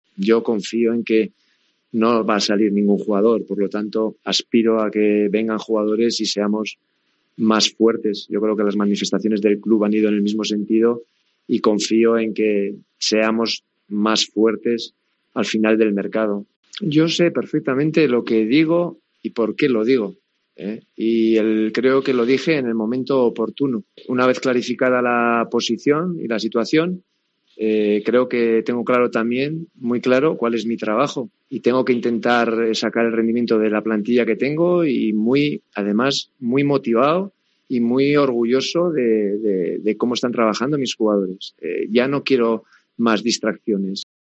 Javi Gracia ha comparecido ante los medios con un mensaje más conciliador y confiando en que no haya más salidas y sí lleguen jugadores que refuercen la plantilla
AUDIO. Así se ha referido Gracia a los asuntos del mercado